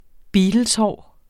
Udtale [ ˈbiːdəlsˌhɒˀ ]